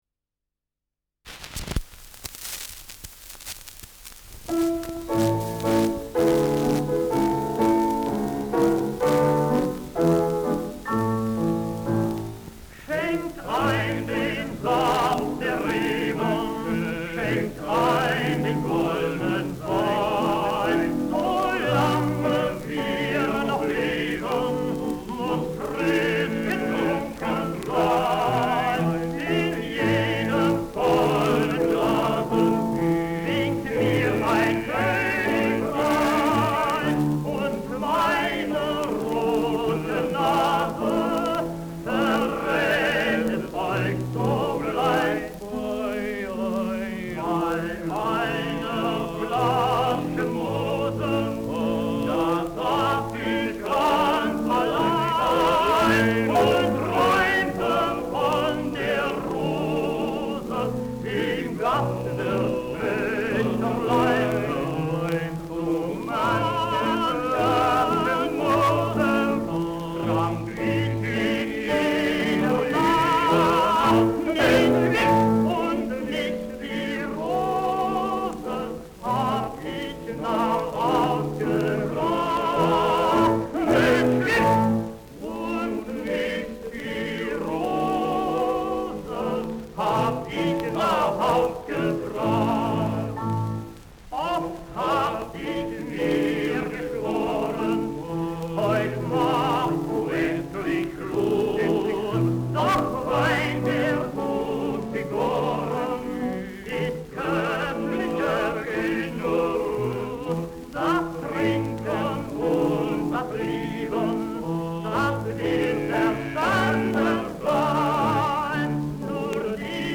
Trinklied
Schellackplatte